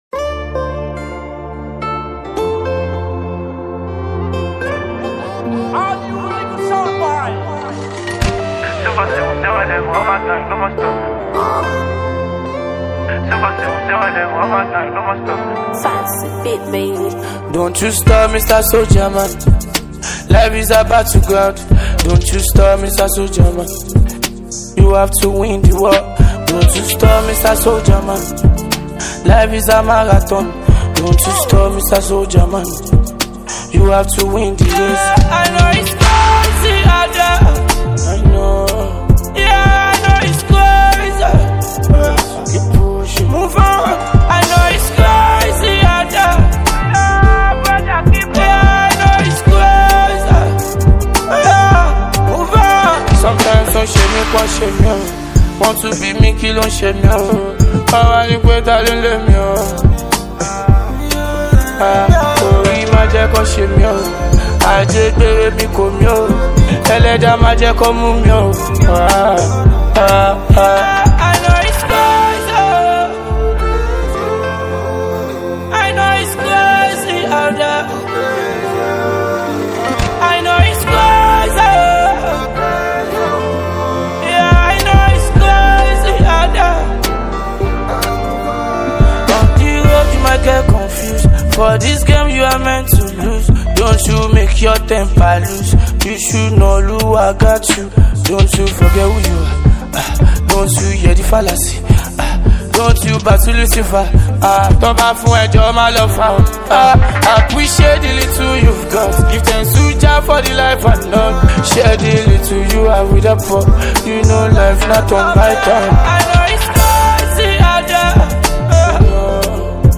radio and club-friendly Jam